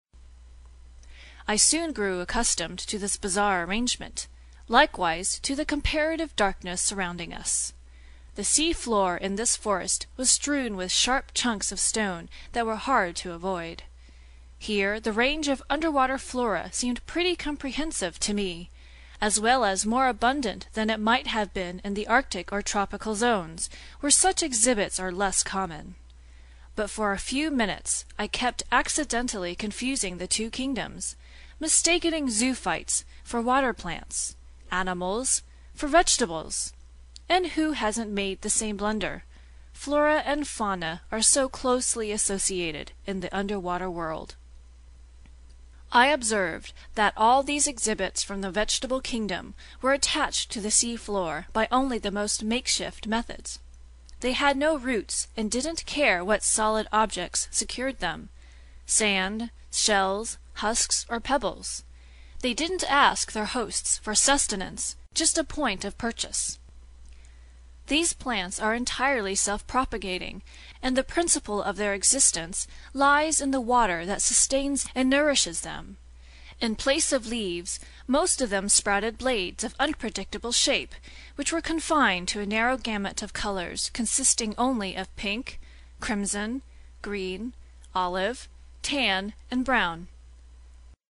在线英语听力室英语听书《海底两万里》第221期 第17章 海底森林(2)的听力文件下载,《海底两万里》中英双语有声读物附MP3下载